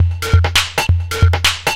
DS 135-BPM A3.wav